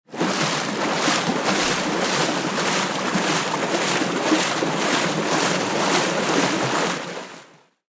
Paddle Boat
yt_4UY2b8ZhB_M_paddle_boat.mp3